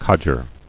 [codg·er]